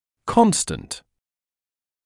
[‘kɔnstənt][‘констэнт]постоянный; неизменный; константа, постоянная величина